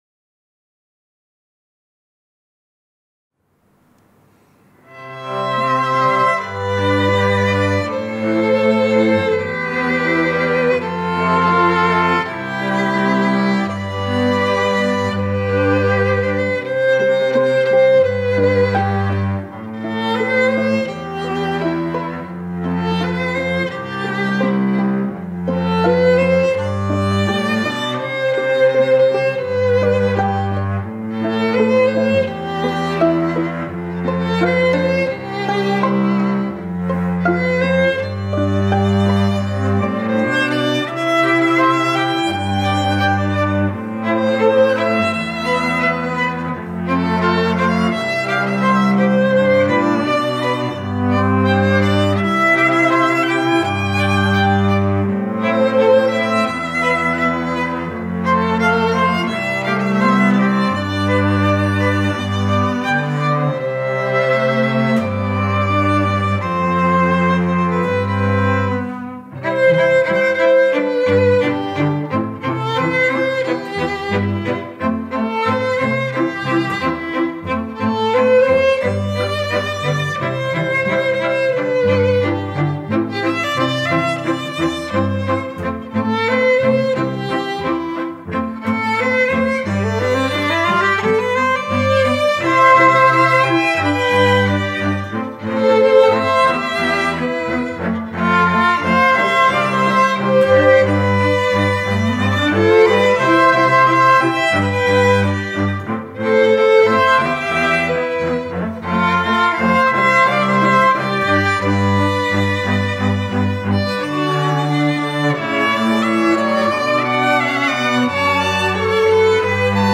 en quatuor à cordes